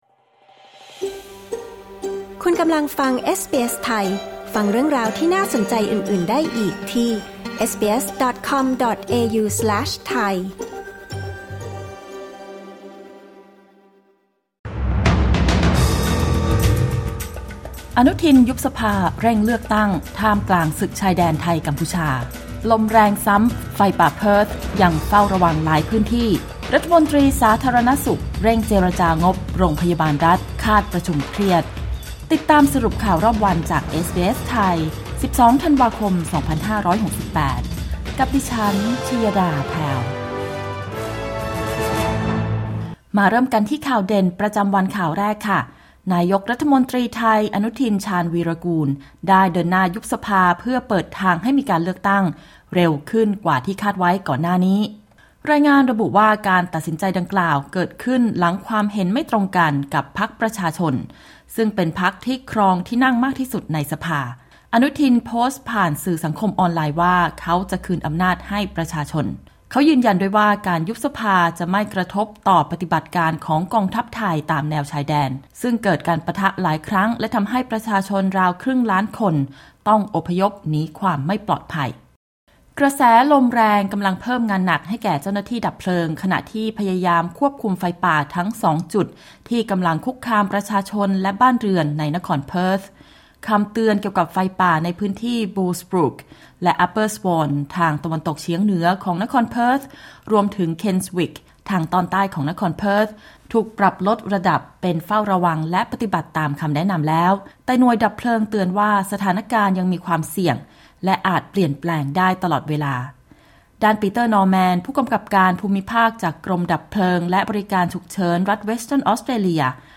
สรุปข่าวรอบวัน 12 ธันวาคม 2568